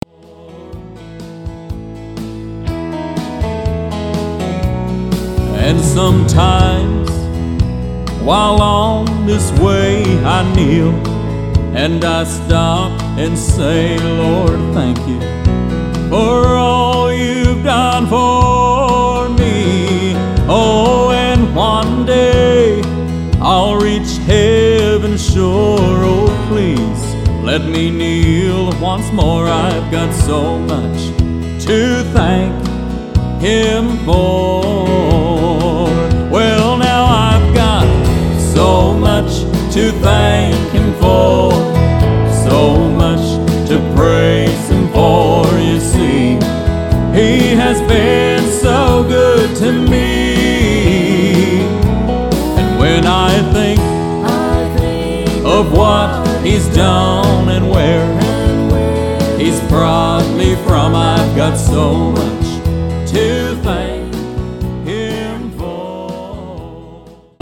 11 Southern Gospel Songs